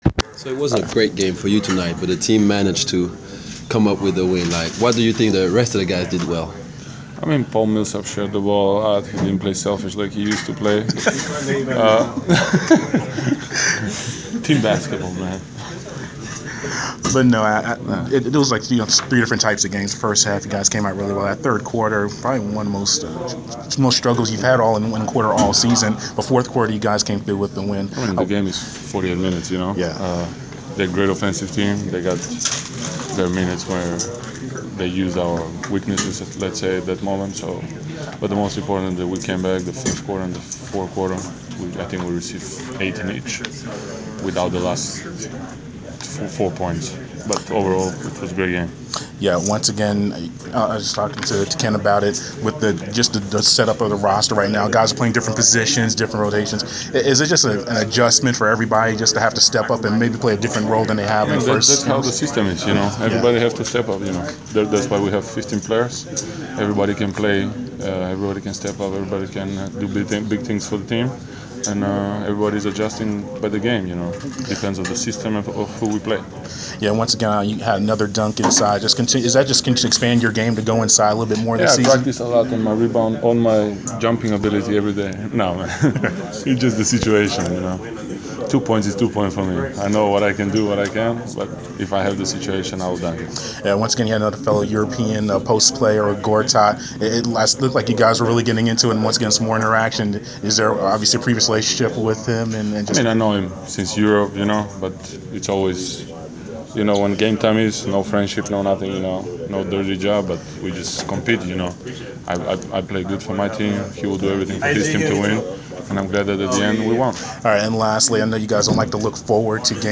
Inside the Inquirer: Postgame interview with Atlanta Hawks’ Pero Antic (2/4/15)
We had an exclusive postgame interview with Atlanta Hawks’ forward/center Pero Antic following his team’s 105-96 home victory over the Washington Wizards on Feb. 4.
Topics included the players adjusting to new roles due to roster injuries, expanding his overall game and anticipation for upcoming Golden State matchup on Feb. 6. We were joined by special guest, teammate Thabo Sefolosha, at the start of the interview.